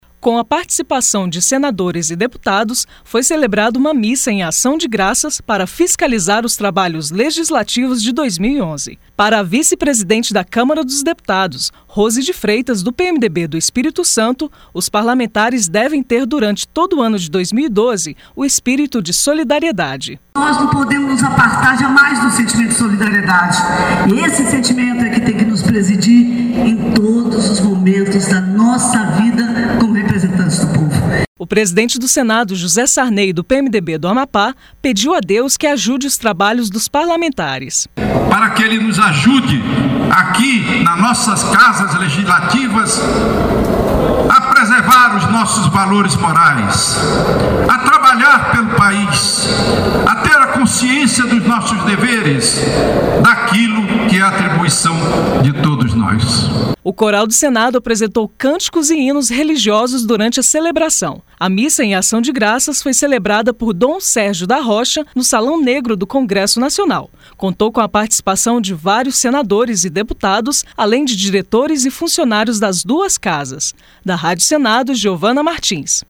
LOC: PARA COMEMORAR O FIM DOS TRABALHOS LEGISLATIVOS, FOI CELEBRADA UMA MISSA EM AÇÃO GRAÇAS, NESTA QUINTA-FEIRA, NO SALÃO NEGRO DO CONGRESSO NACIONAL.